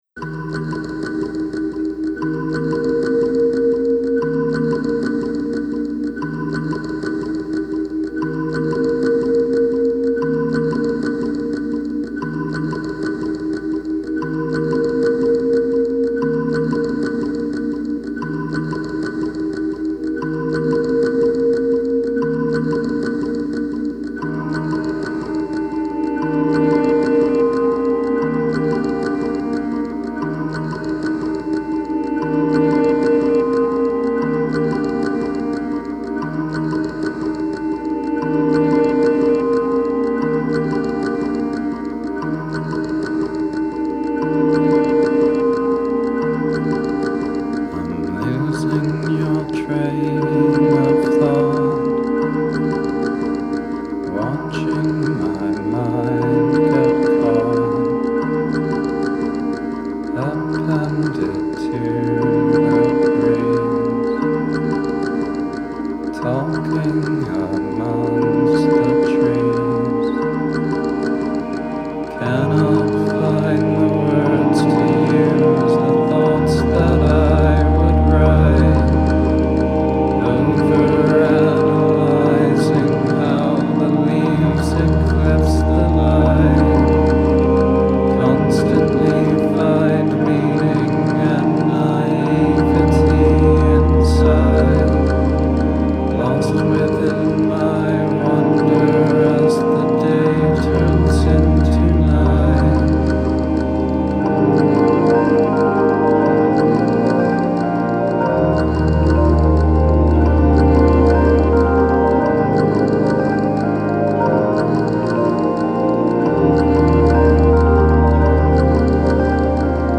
ambient records